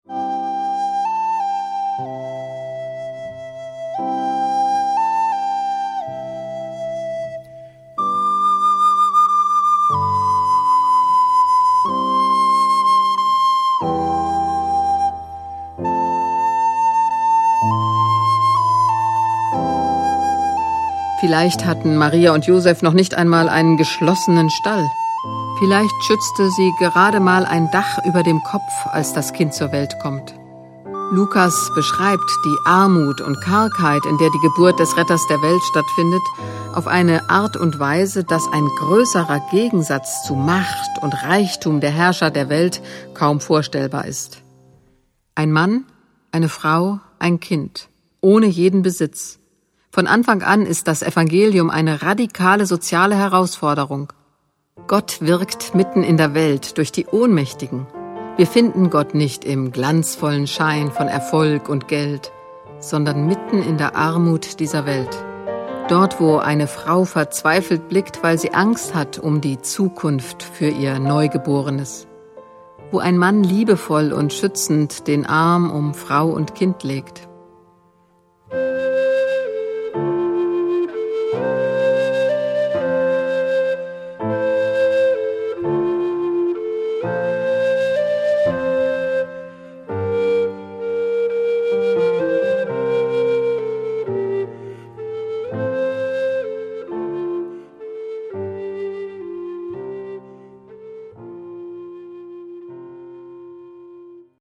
Hörspiel in Text und Musik: